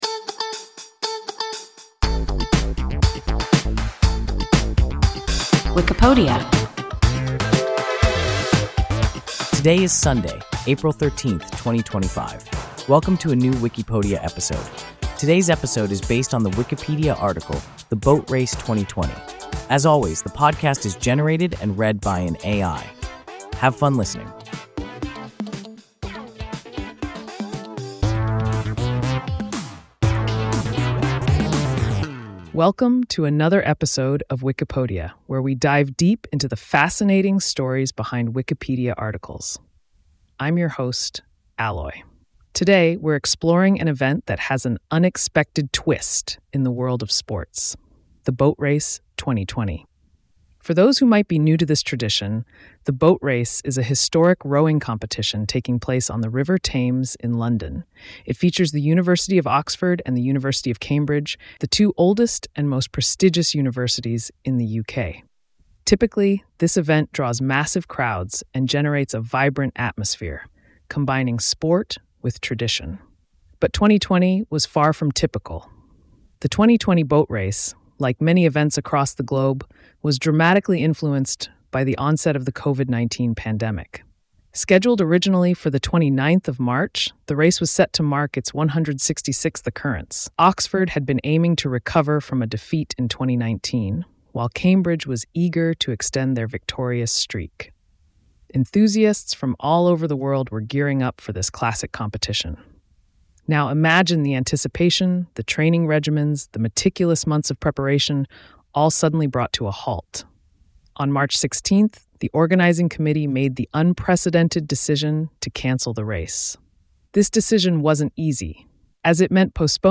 The Boat Race 2020 – WIKIPODIA – ein KI Podcast